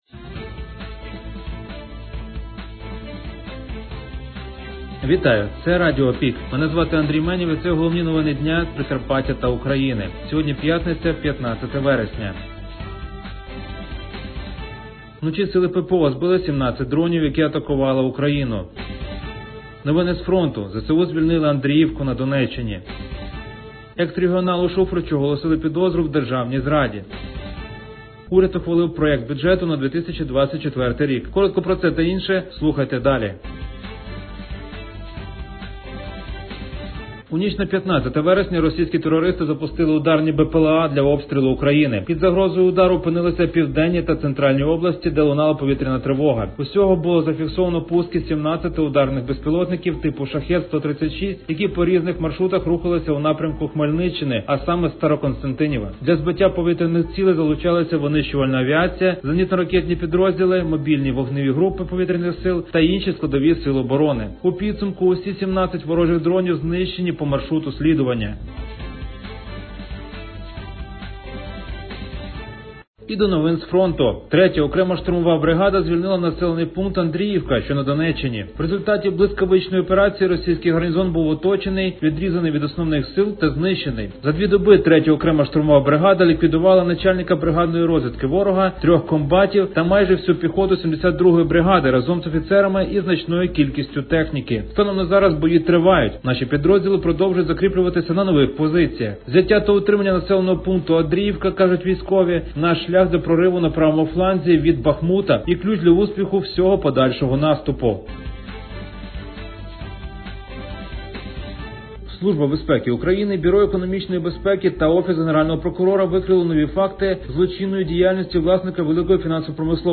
ропонуємо Вам актуальне за день у радіоформаті.